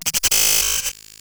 Glitch FX 17.wav